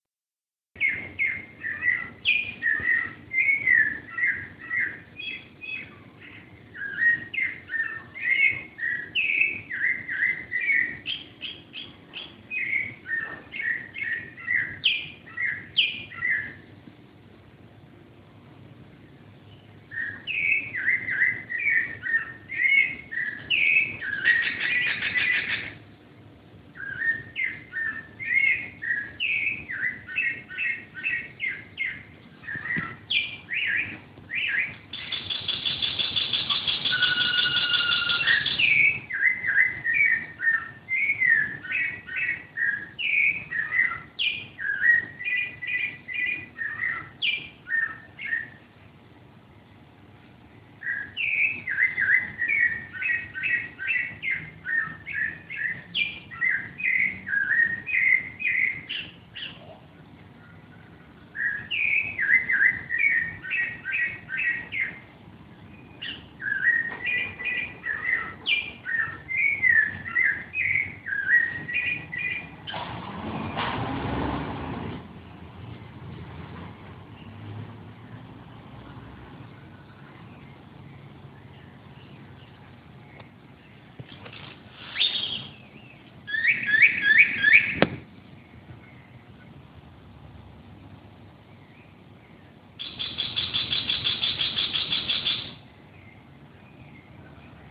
Canto al amanecer